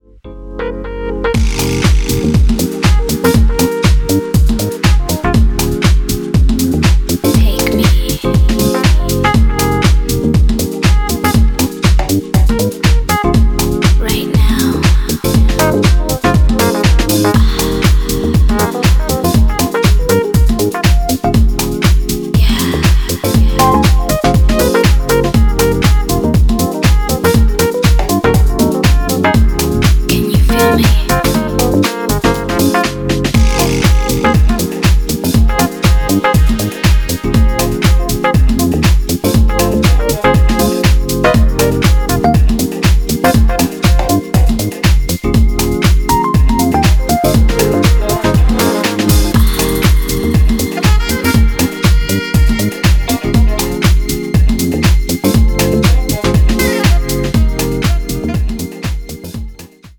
トランシーなハウスのセットにオーセンティックな彩りを与えてくれそうな